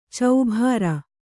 ♪ caubhāra